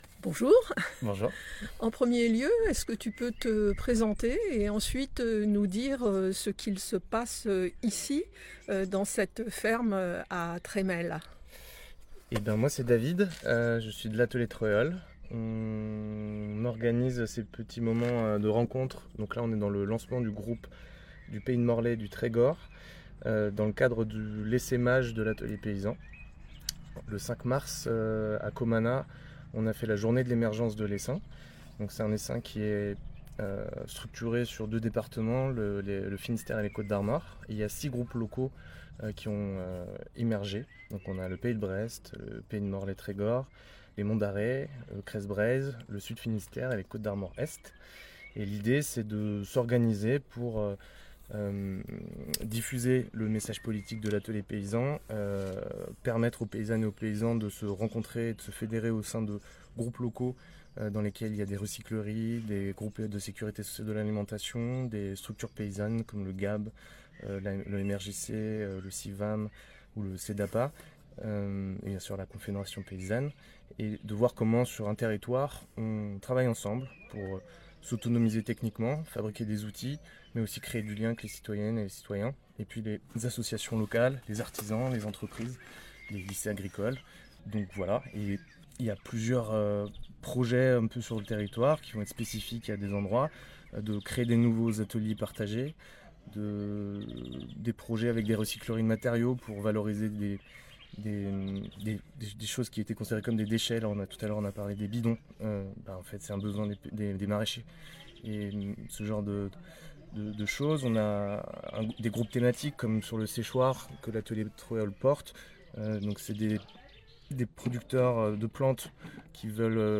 reportage-essaimage-atelier-paysan-morlaix-tregor.mp3